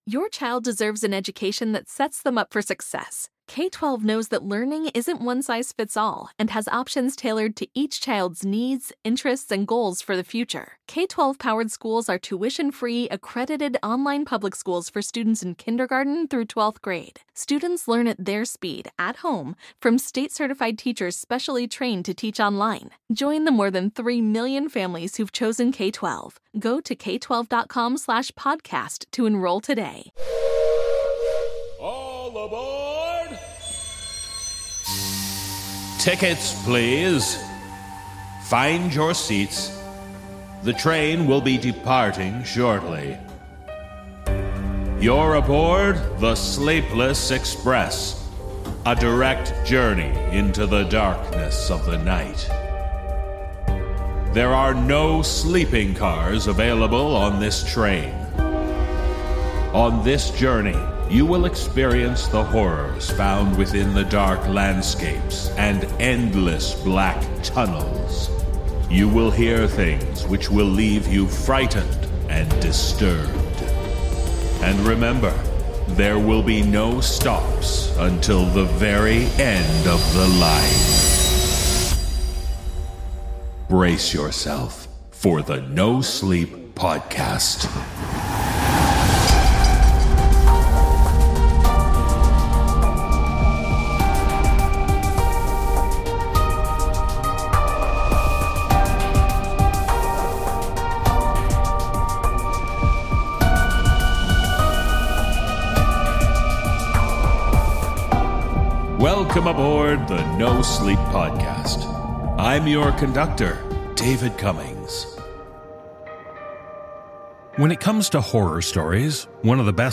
Cast: Narrator